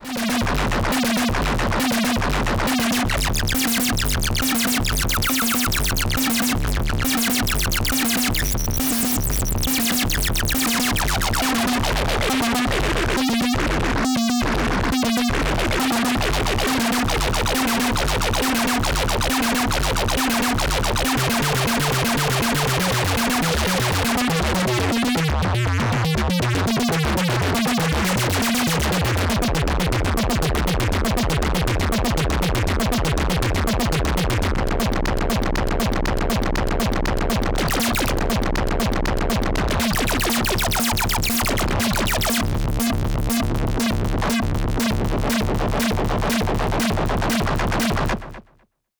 Opsix Wavefold Acid Doodle